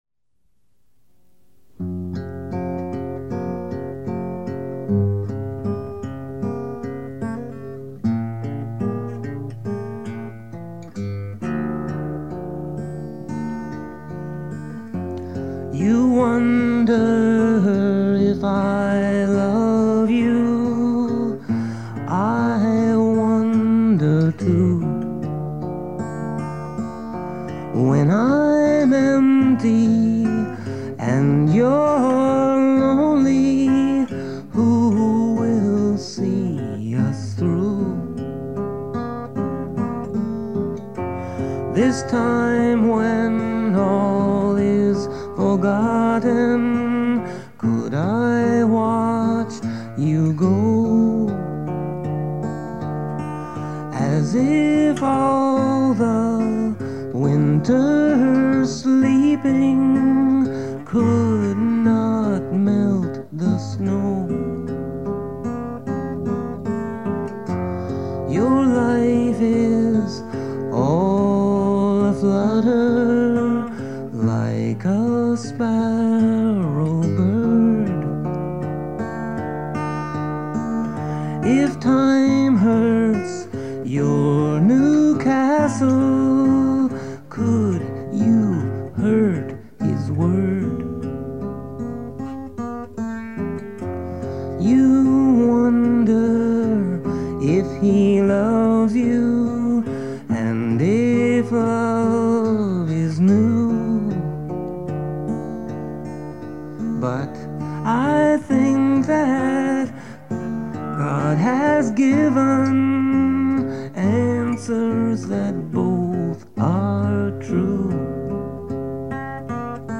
You-Should-Wonder-Too-Studio-Version.mp3